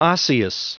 Prononciation du mot osseous en anglais (fichier audio)
Prononciation du mot : osseous